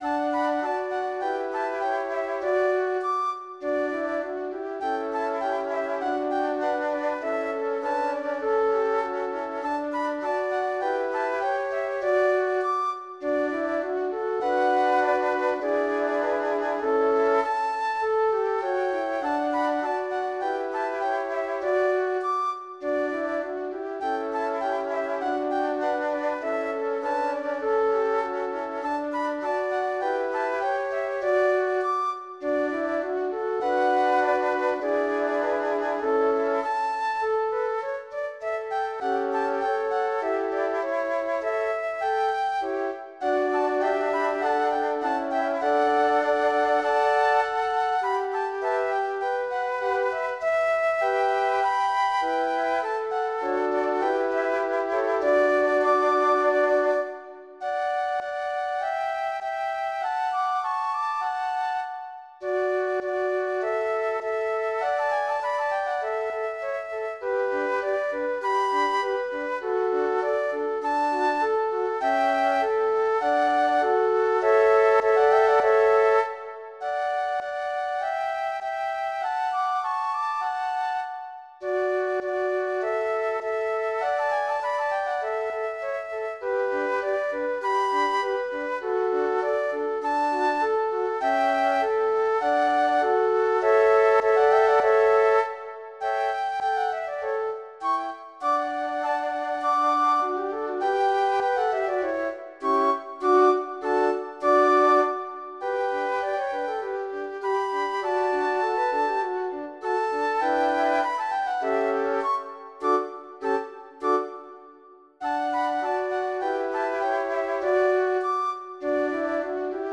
ASCOLTA E SCARICA GRATUITAMENTE LE DEMO
per quattro flauti traversi in do